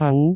speech
syllable
pronunciation
aau2.wav